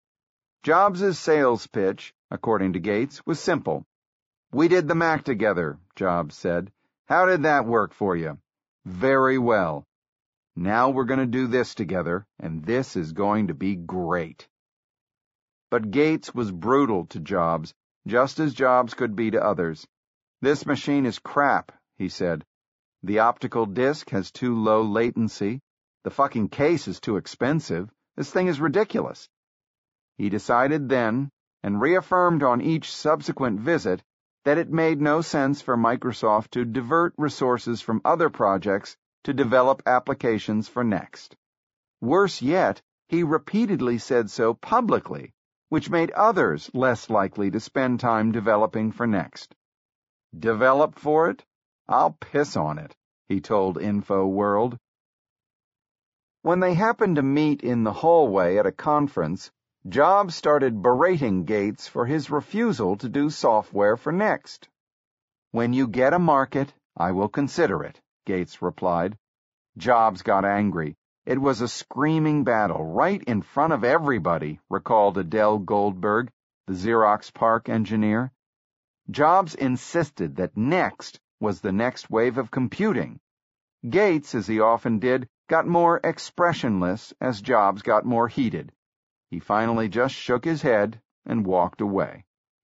在线英语听力室乔布斯传 第241期:盖茨和NeXT(2)的听力文件下载,《乔布斯传》双语有声读物栏目，通过英语音频MP3和中英双语字幕，来帮助英语学习者提高英语听说能力。
本栏目纯正的英语发音，以及完整的传记内容，详细描述了乔布斯的一生，是学习英语的必备材料。